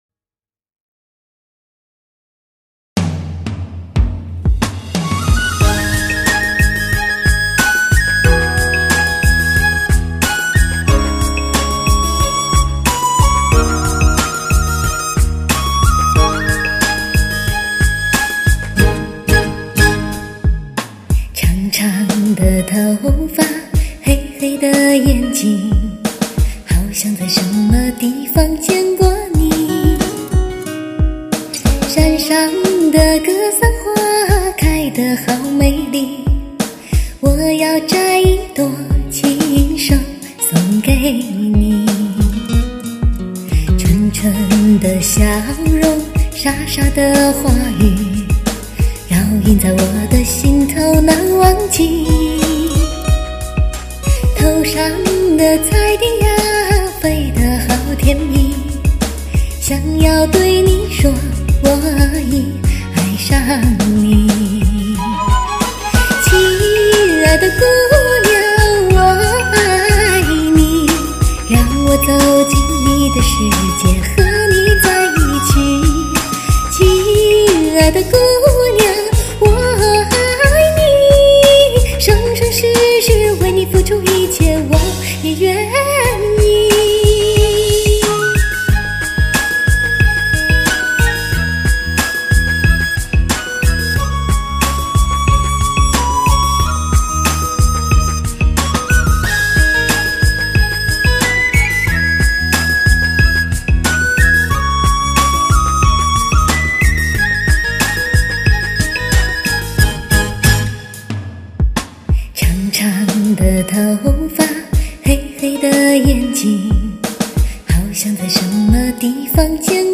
原生态藏地民歌，原生态音乐的灵性呼唤。电子乐特有的节奏冲击，无间融合形成巨大听觉震撼，醒醐灌顶，荡涤心灵。